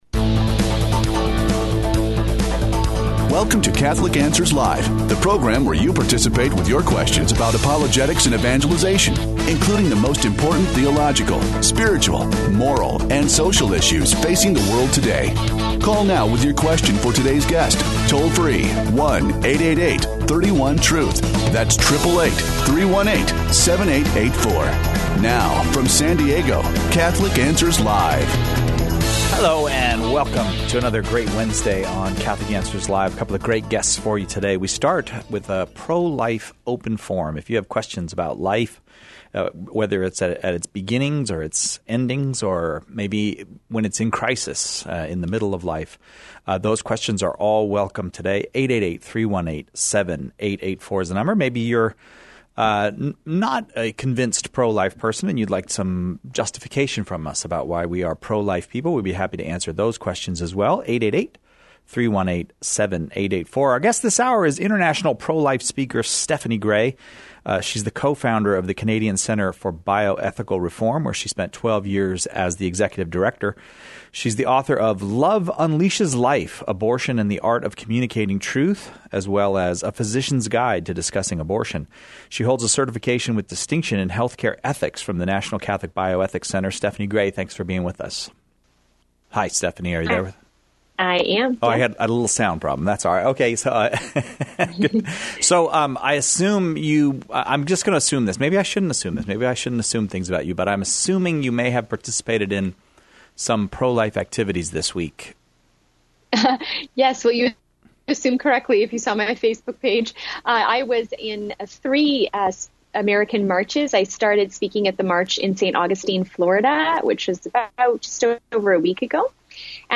Callers share their questions about pro-life issues from conception to natural death. Questions Covered: 05:48 - Do “married” gay people have a place in solving the abortion problem by adopting children who would otherwise be aborted? 12:11 - Is it incongruent to be pro-life and yet accept the death penalty?